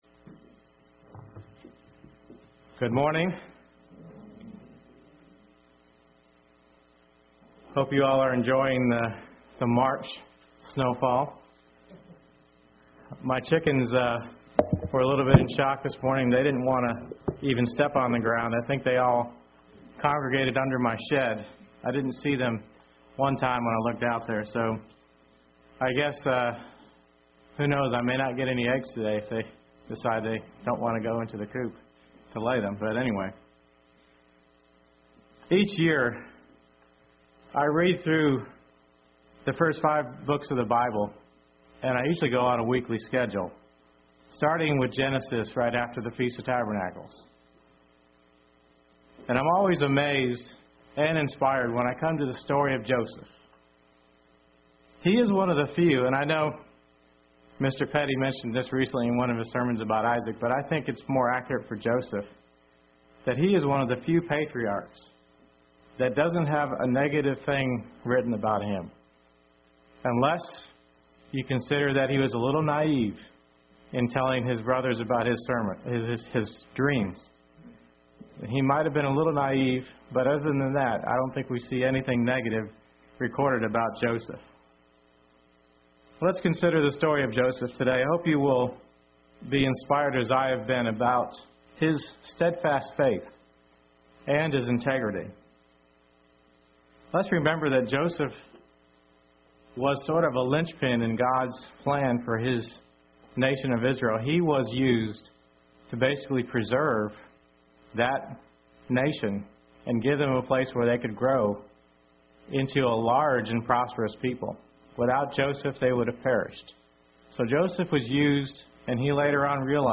Sermons
Given in Murfreesboro, TN